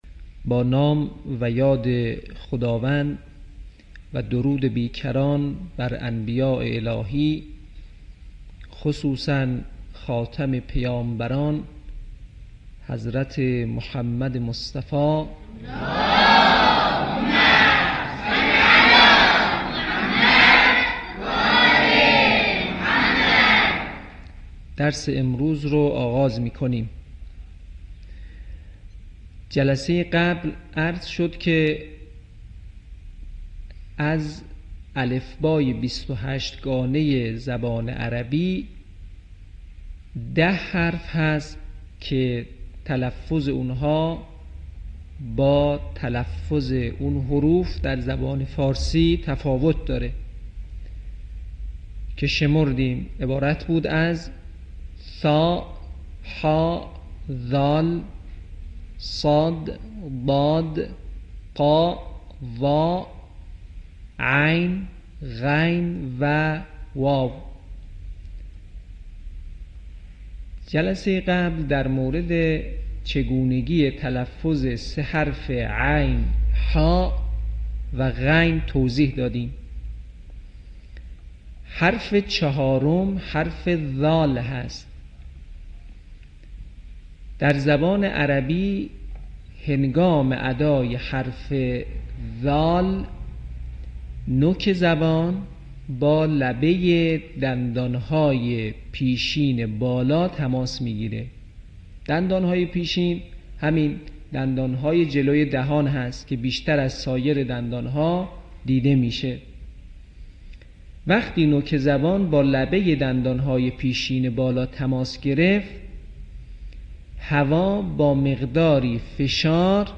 صوت | آموزش تلفظ حرف ذال